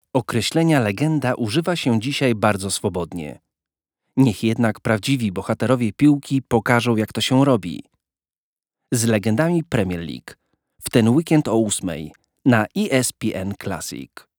Comercial, Amable, Cálida, Suave, Empresarial
Corporativo
At the same time, with very good diction.